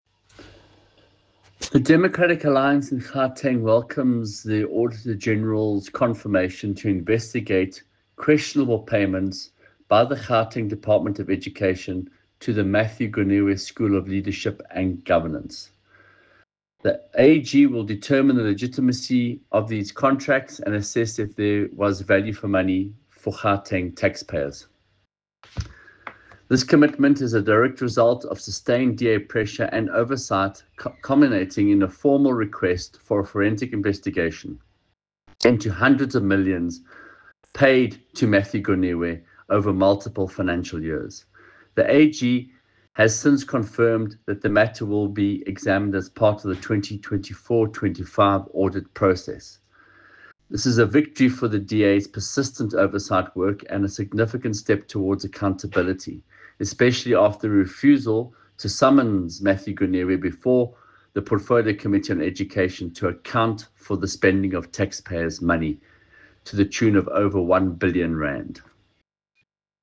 soundbite by Michael Waters MPL.